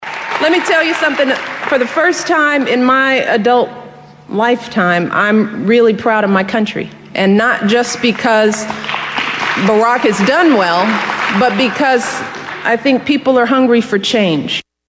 If you haven’t heard, the speaker was Michelle Obama to a campaign crowd in Wisconsin week before last.
Because she said it again and this time we have it on tape.